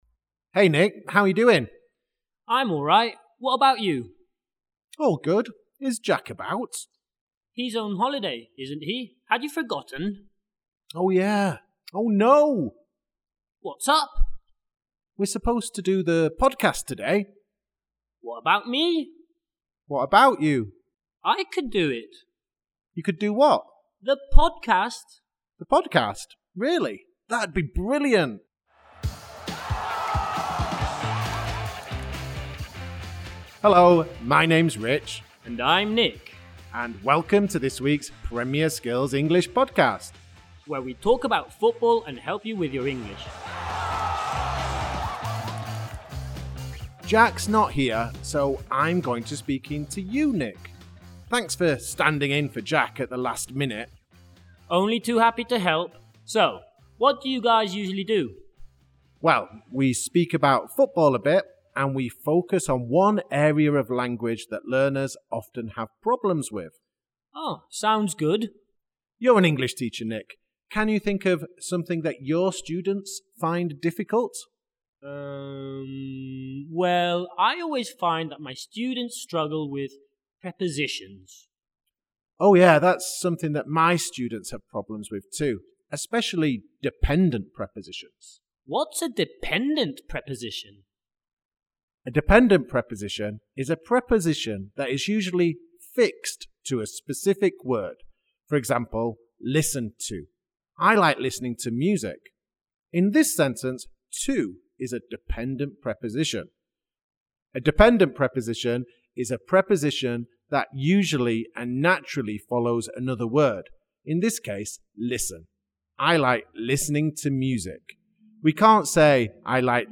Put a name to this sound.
Skills: Listening